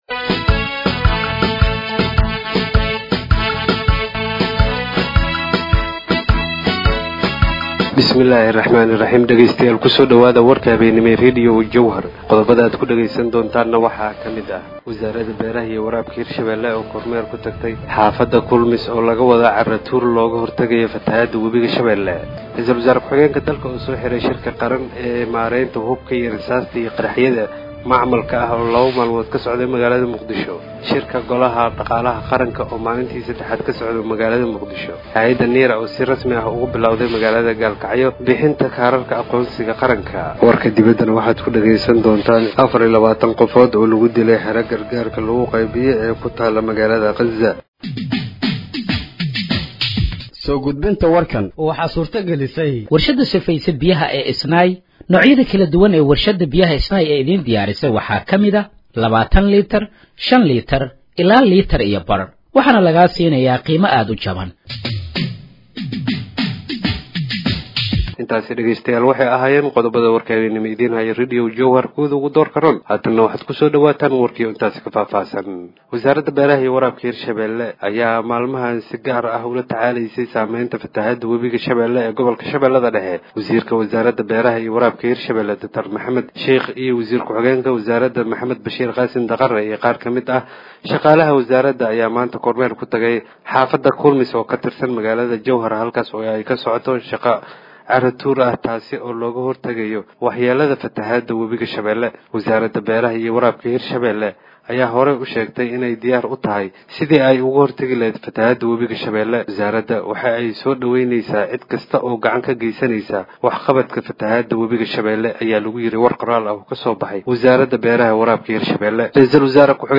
Dhageeyso Warka Habeenimo ee Radiojowhar 03/06/2025